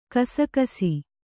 ♪ kasakasi